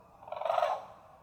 Contact Call | A soft, purring call expressing reassurance and location.
Brolga-Contact.mp3